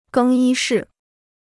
更衣室 (gēng yī shì) Free Chinese Dictionary